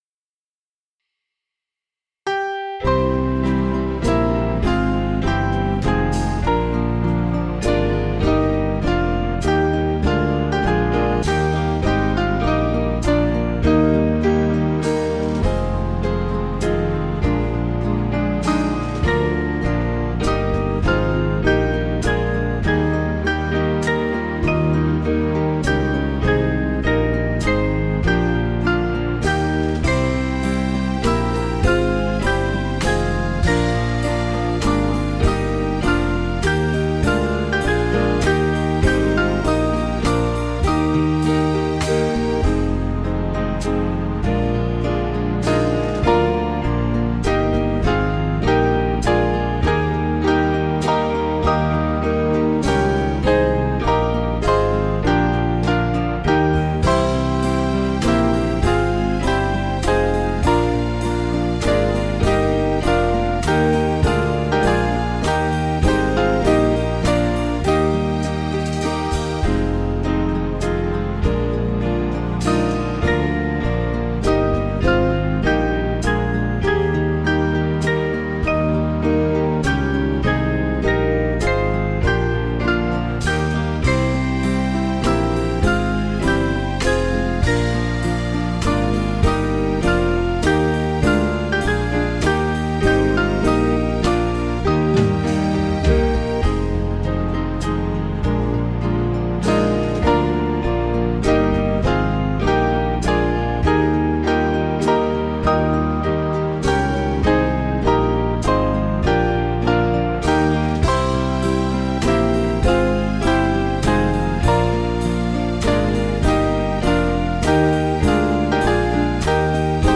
Small Band
(CM)   5/Eb 486.1kb